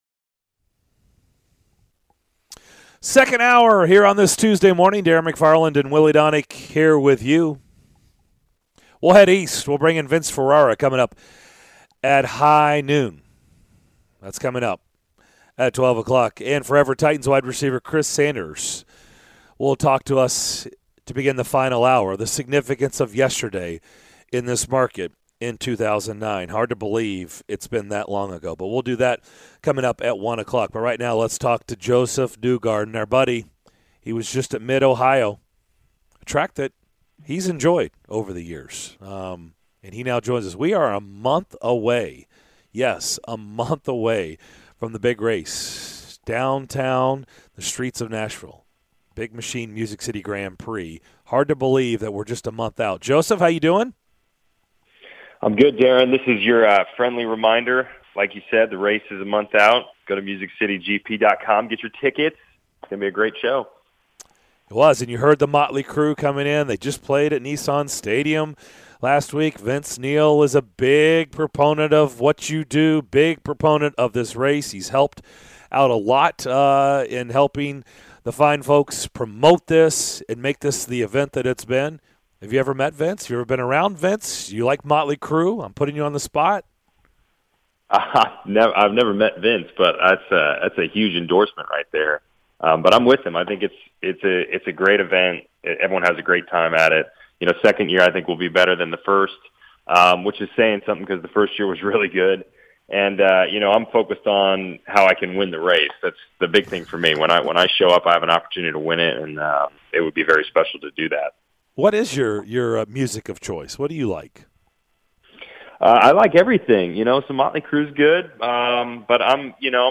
Josef Newgarden Interview